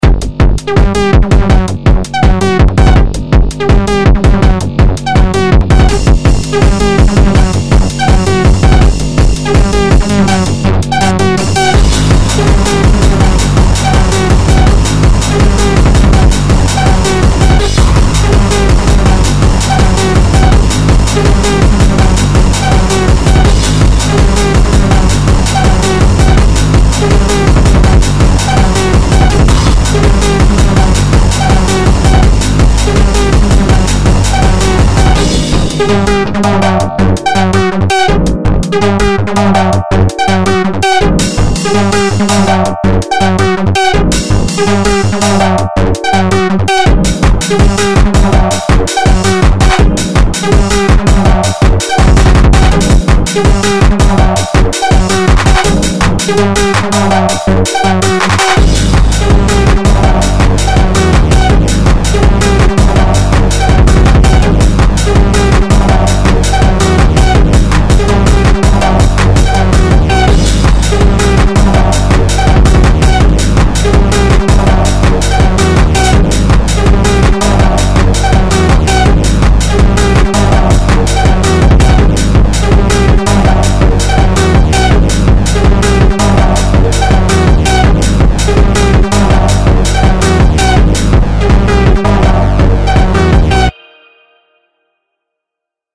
DIGITAL HARDCORE